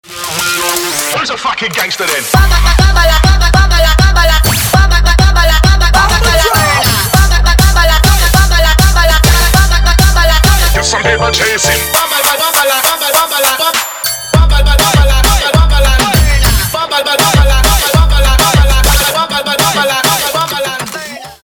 Trap
трэп